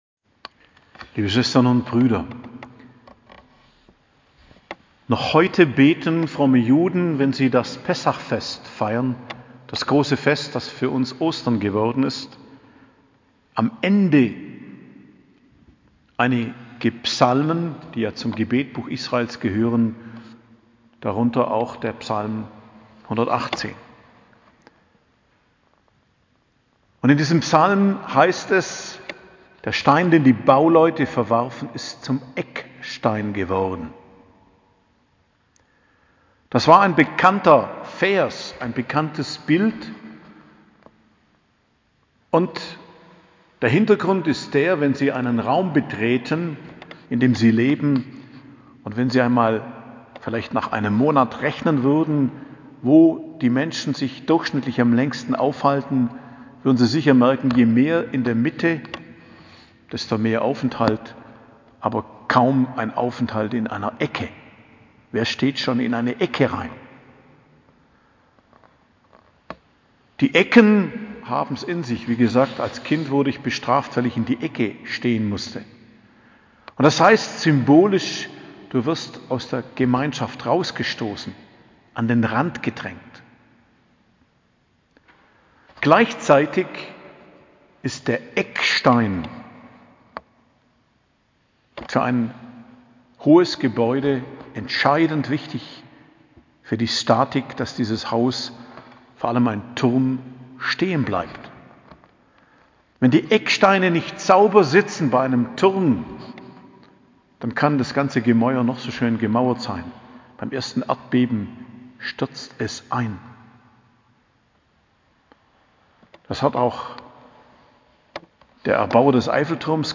Predigt am Freitag der 2. Woche der Fastenzeit, 18.03.2022